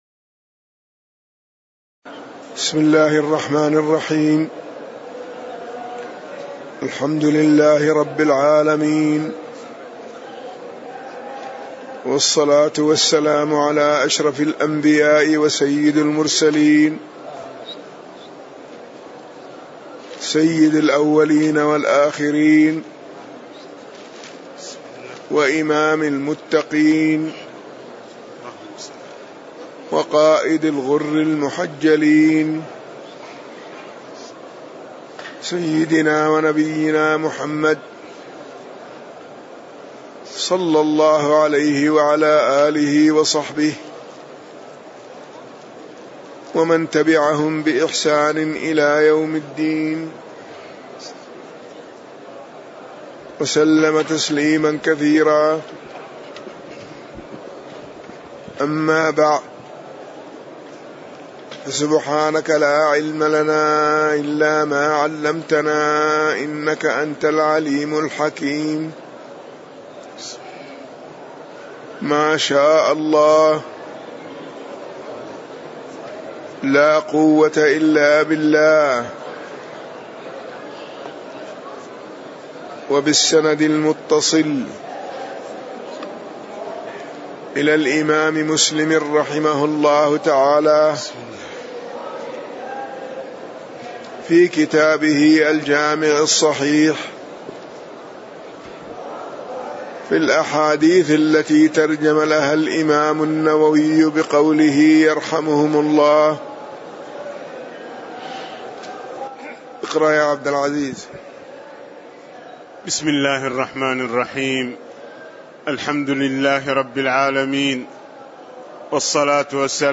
تاريخ النشر ٢١ رجب ١٤٣٨ هـ المكان: المسجد النبوي الشيخ